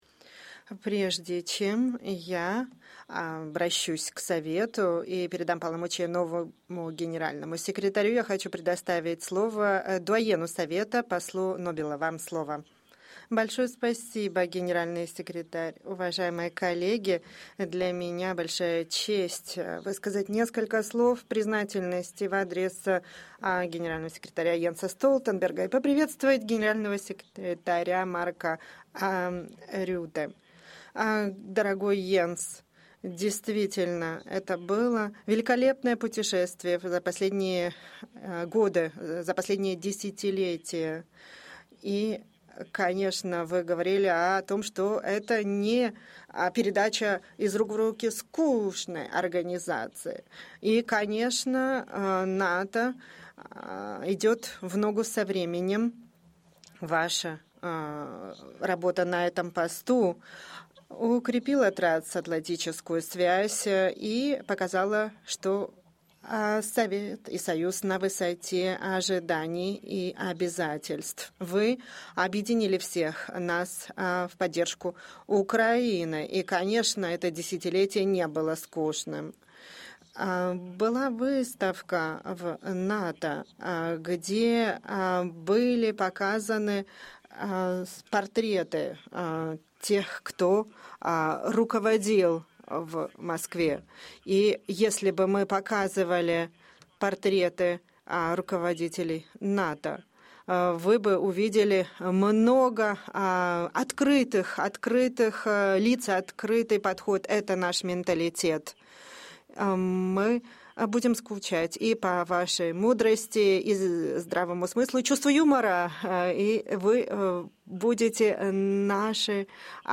in the North Atlantic Council by NATO Secretary General Jens Stoltenberg followed by Secretary General Designate Mark Rutte